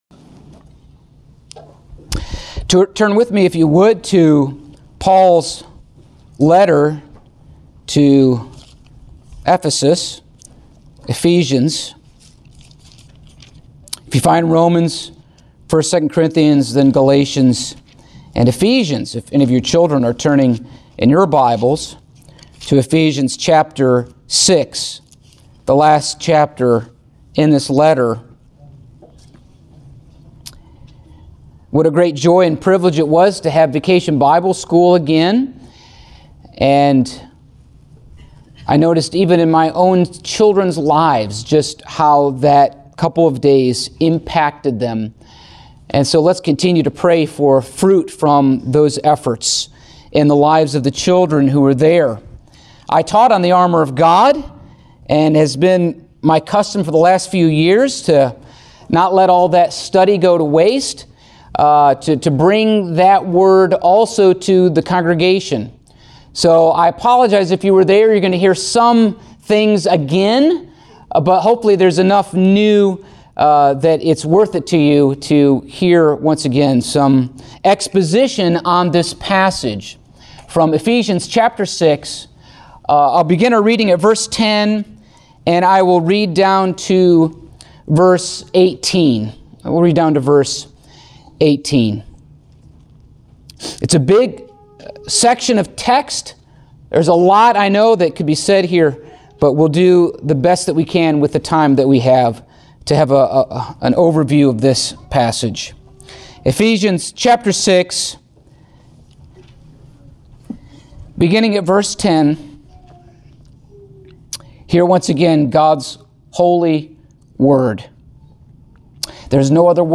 Passage: Ephesians 6:10-18 Service Type: Sunday Morning